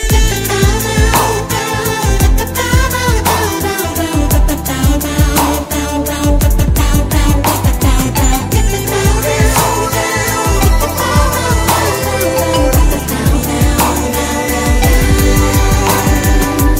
CategoryTelugu Ringtones